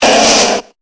Cri d'Ortide dans Pokémon Épée et Bouclier.